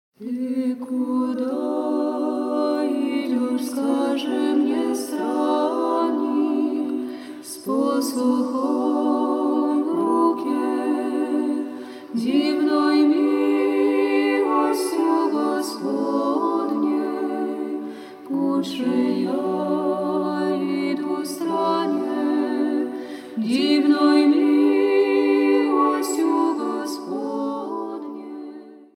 з духовном музыком